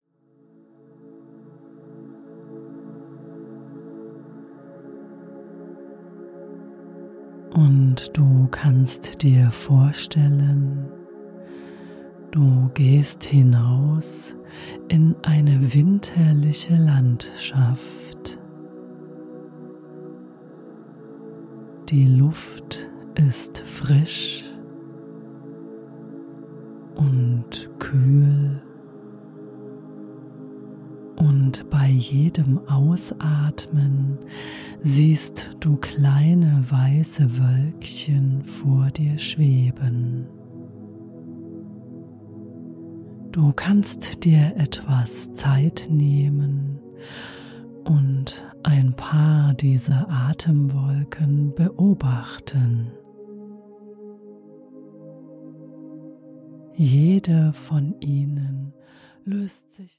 Du wirst einfühlsam dazu eingeladen und angeleitet, dich mit jedem Atemzug mehr zu entspannen und die Bilder und Klänge des Winters in dir wirken zu lassen. Die beruhigende Atmosphäre soll dir mehr Geborgenheit, Gelassenheit und einen Moment der inneren Stille schenken.